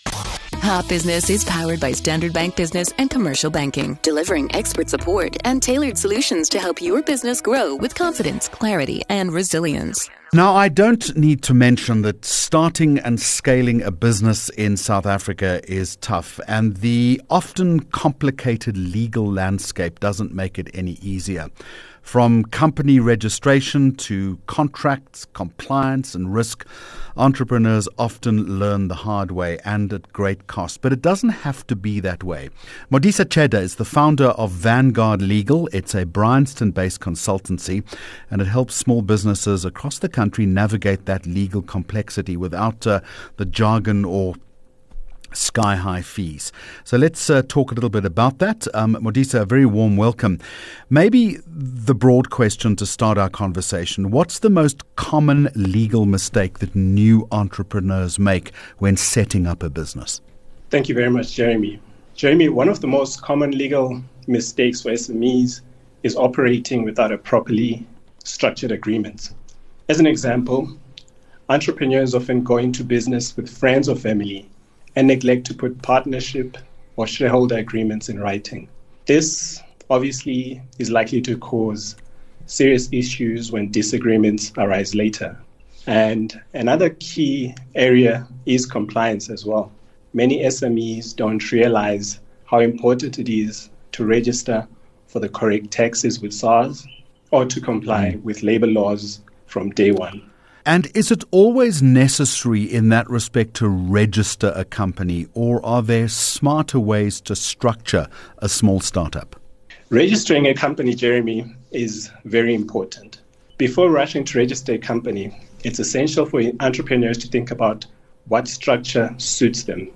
23 Jun Hot Business Interview